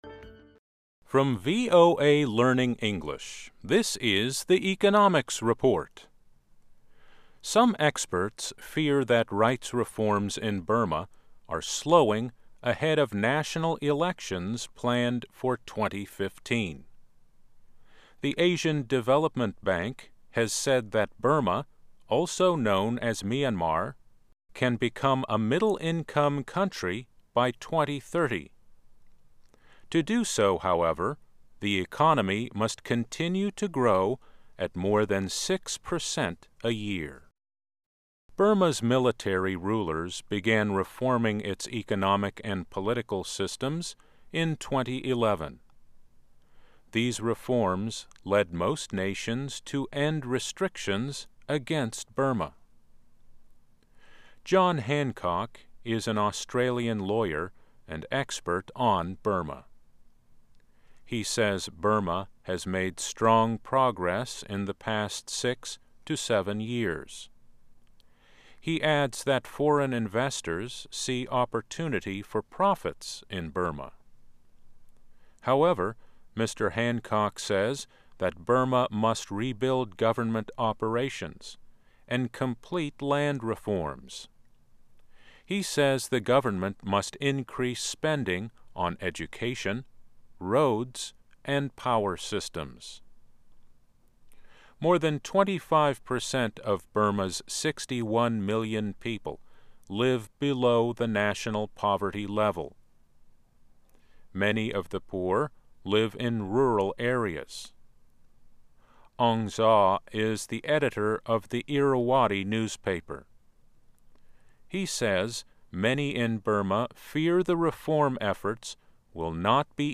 Learn English as you read and listen to news and feature stories about business, finance and economics. Our daily stories are written at the intermediate and upper-beginner level and are read one-third slower than regular VOA English.